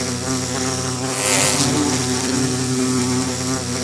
Minecraft Version Minecraft Version snapshot Latest Release | Latest Snapshot snapshot / assets / minecraft / sounds / mob / bee / aggressive1.ogg Compare With Compare With Latest Release | Latest Snapshot
aggressive1.ogg